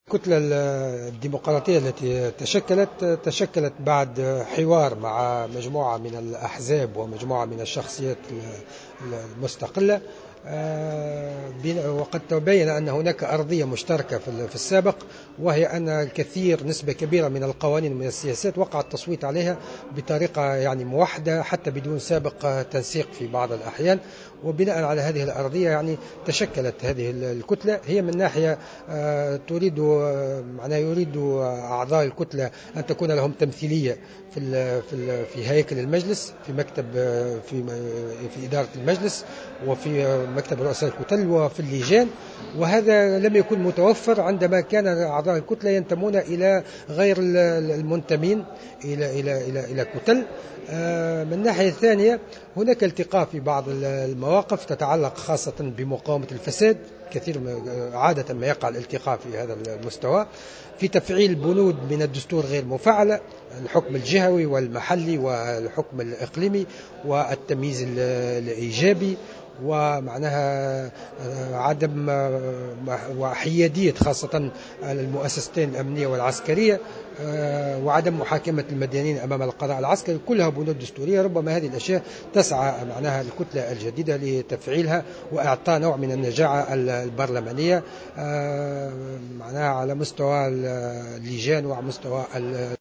وأضاف في تصريح لمراسل "الجوهرة أف أم" اليوم الأربعاء أن كتلة "الديمقراطية" تشكلت بعد حوار مع أحزاب ومجموعة من الشخصيات المستقلة وبعد أن تبين أن هناك أرضية مشتركة بينها عند المصادقة على قوانين سابقة دون تنسيق مسبق.